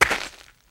High Quality Footsteps / Gravel
STEPS Gravel, Walk 08.wav